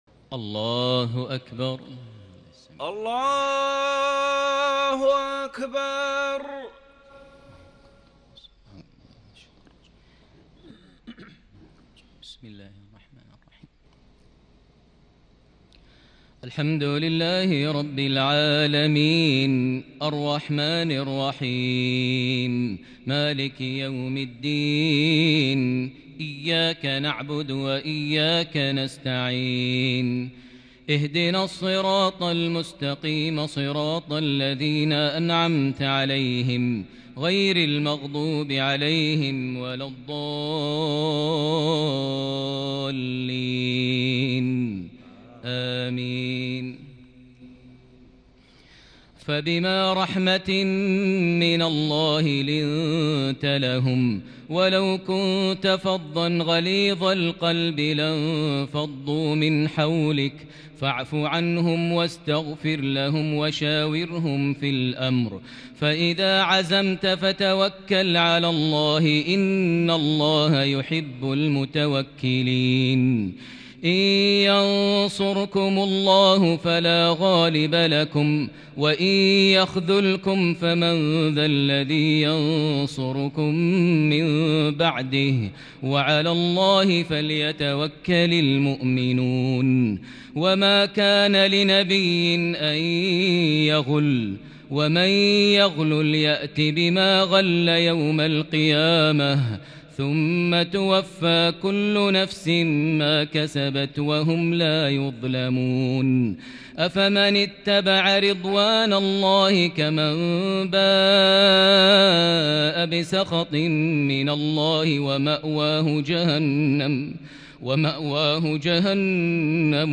تراويح ليلة 5 رمضان 1441 من سورة آل عمران {159-200} Taraweeh 5st night Ramadan 1441H Surah Aal-i-Imraan > تراويح الحرم المكي عام 1441 🕋 > التراويح - تلاوات الحرمين